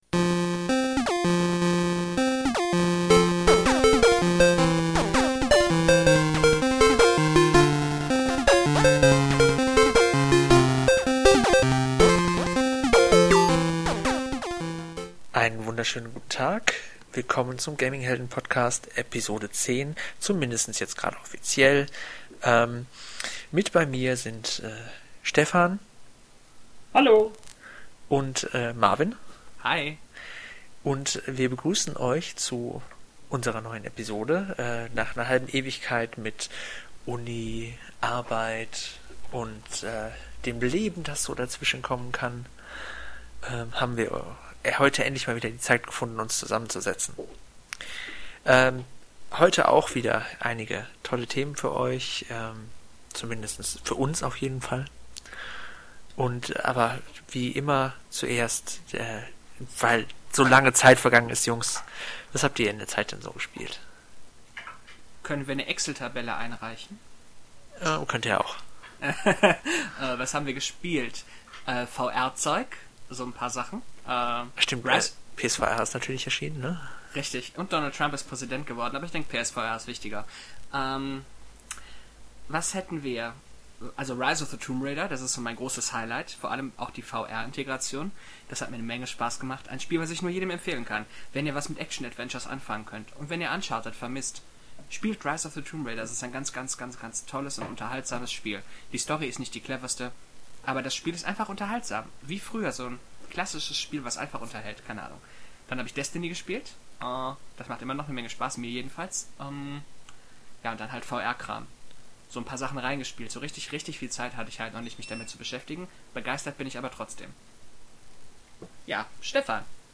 Und entschuldigt bitte den Hall-Effekt der ein paar mal Auftritt, wir versuchen den Podcast mit einer besseren Tonqualität zu produzieren, aber wir haben noch nicht so ganz die richtige Lösung gefunden.